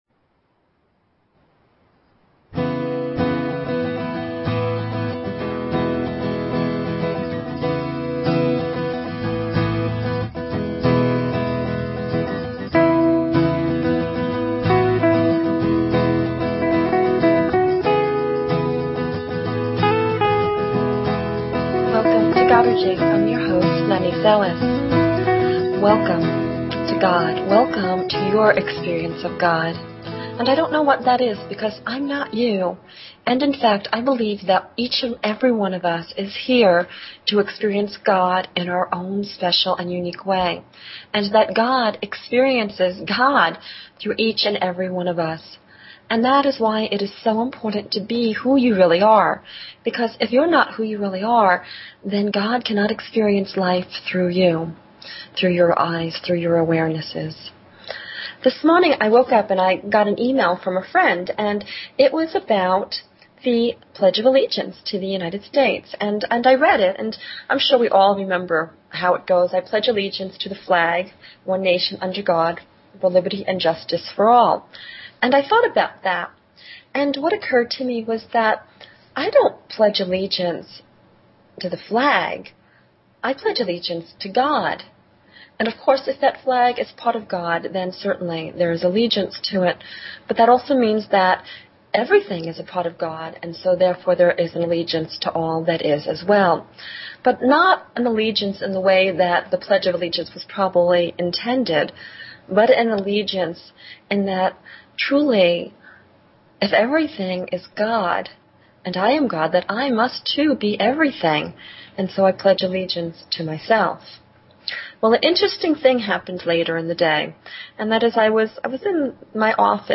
Talk Show Episode, Audio Podcast, Godergy and Courtesy of BBS Radio on , show guests , about , categorized as
Opening Meditation and Closing Prayer Circle.